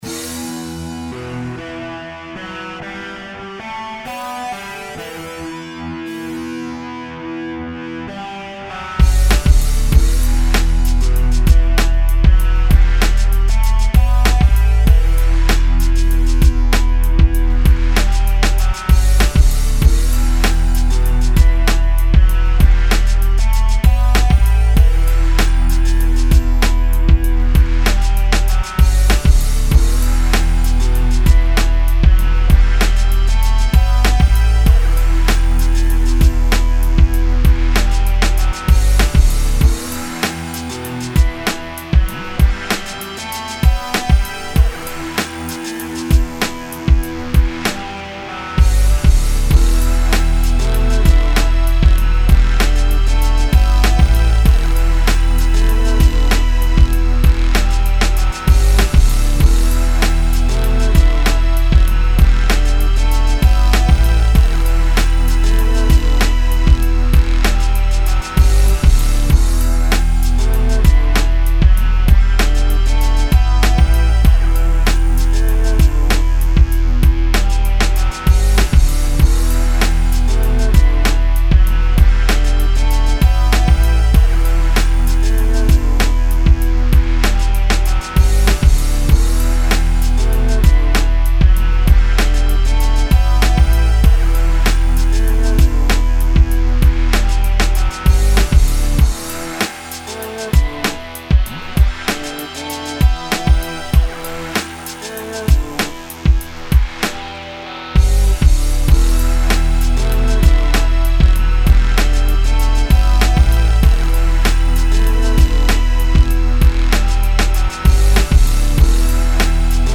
97 BPM.